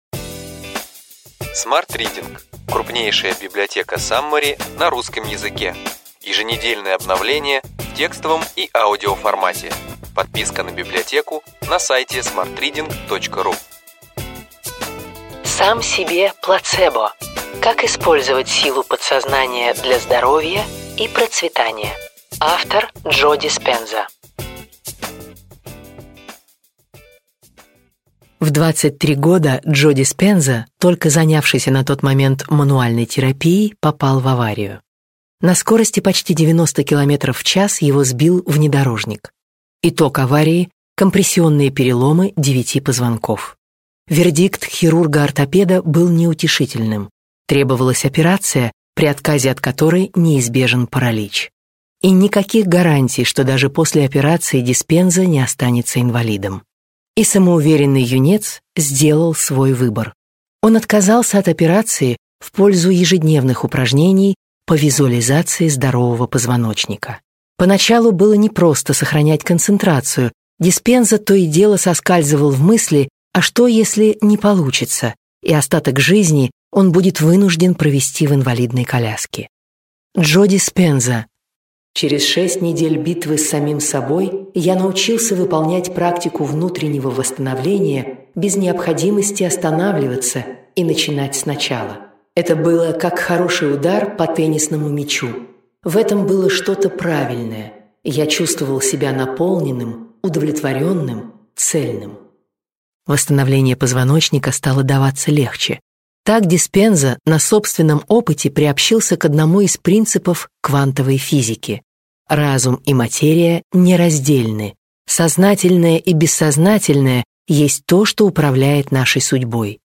Аудиокнига Ключевые идеи книги: Сам себе плацебо. Как использовать силу подсознания для здоровья и процветания. Джо Диспенза | Библиотека аудиокниг